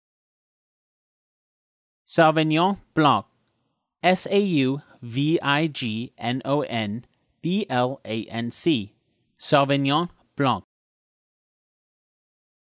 Editor’s Note: This post contains user-submitted pronunciations.
soh-vin-yohn blahn